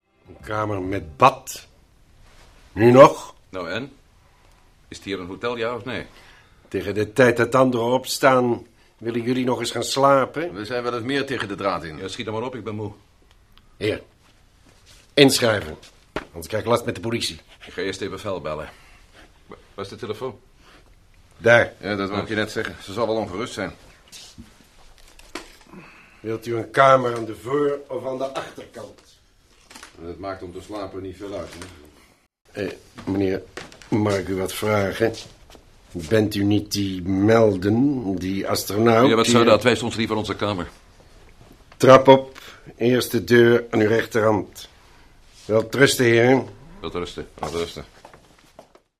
Matt Meldon hoorspelheld
Erg vriendelijk verloopt de conversatie niet.